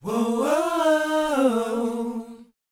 WHOA A#A.wav